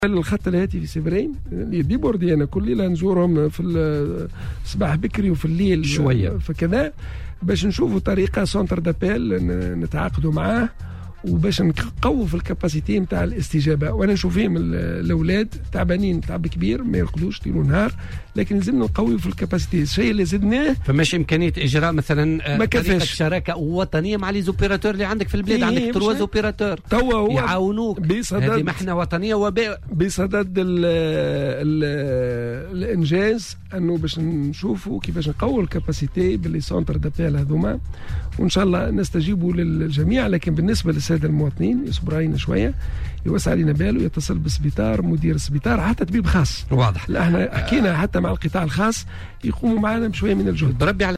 وأضاف في مداخلة له اليوم في برنامج "بويليتكا" أن هناك ضغطا كبيرا على هذا الخط، وأن الوزارة بصدد البحث عن حلول من اجل الاستجابة لمختلف الإشعارات، مشيرا أيضا إلى أنه بإمكان الأشخاص الذين يشعرون بأعراض المرض الاتصال بالمستشفيات أو بطبيب خاص، وفق قوله.